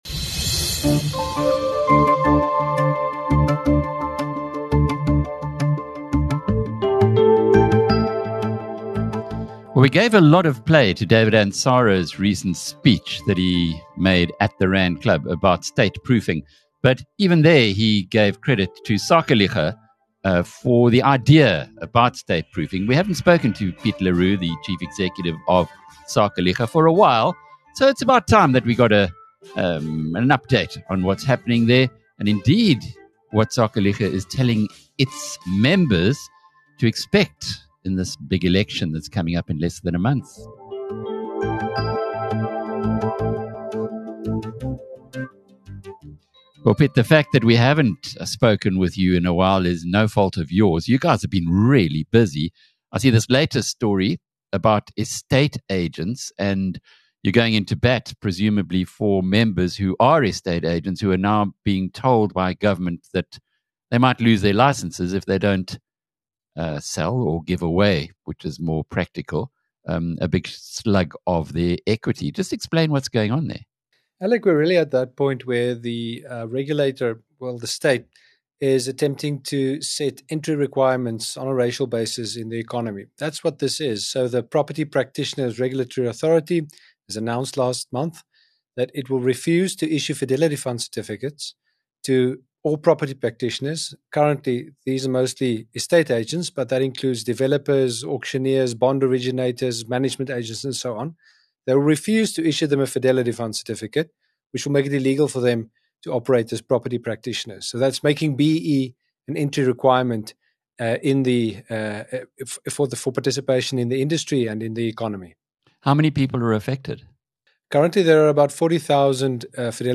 In this powerful interview